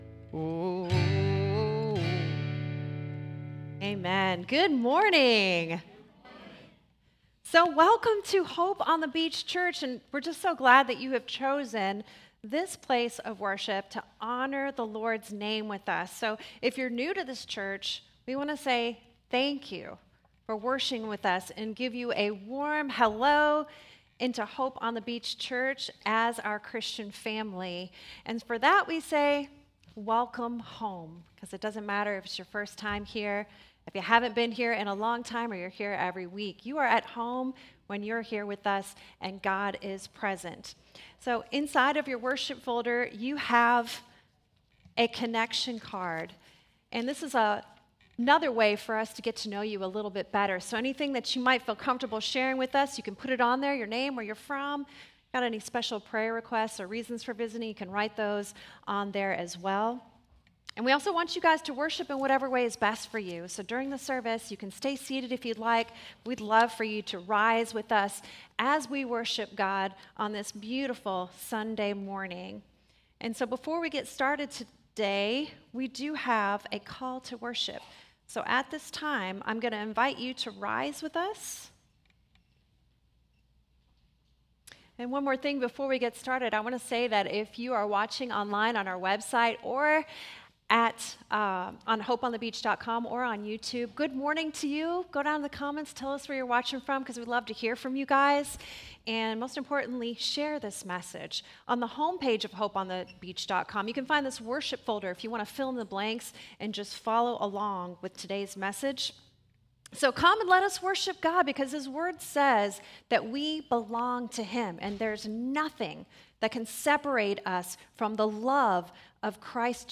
SERMON DESCRIPTION In Book of Hosea 14:4–8, we see God’s steadfast love on full display—He heals our wandering hearts and loves us freely.